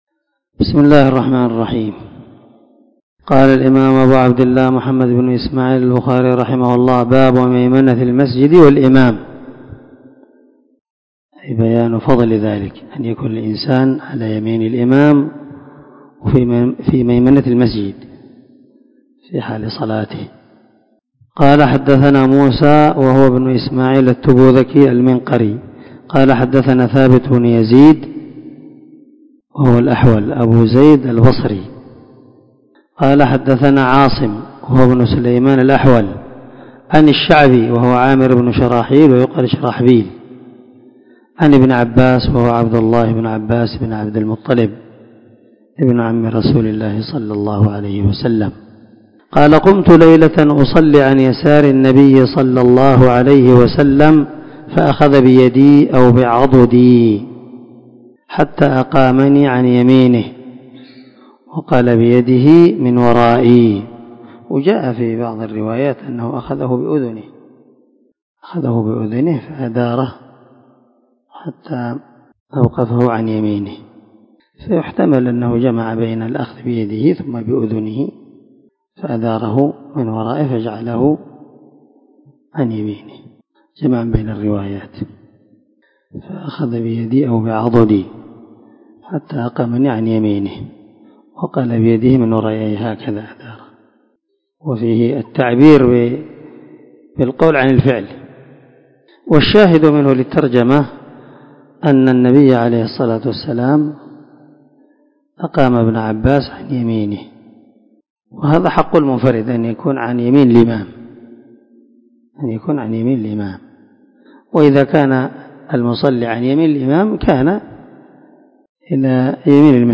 482الدرس65من شرح كتاب الأذان حديث رقم (728_729)من صحيح البخاري
دار الحديث- المَحاوِلة- الصبيحة.